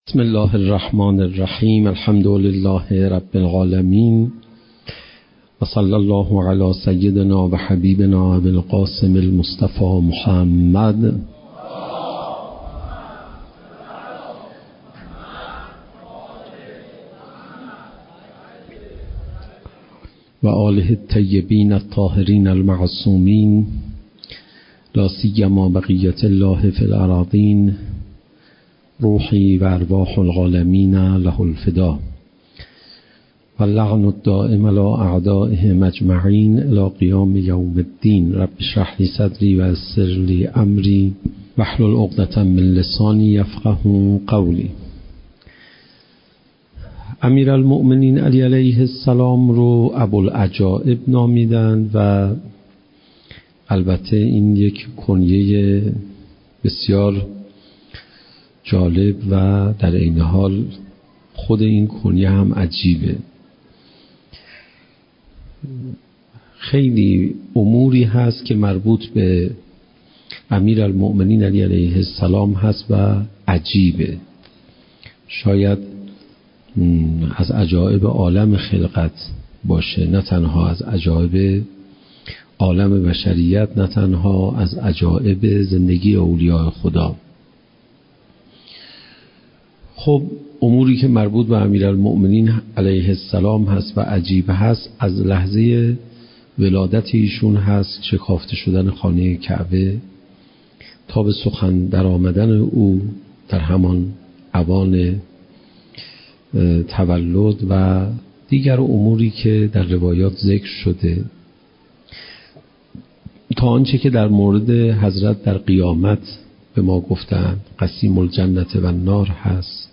اولین قسمت از بیانات ارزشمند